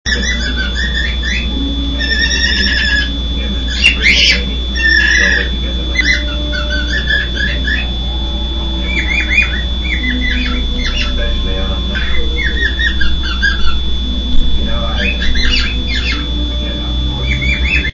Cute little grey cockatiel on a dreser.
sound button to hear a real sound clip of Tookiebird wav file of Tookie!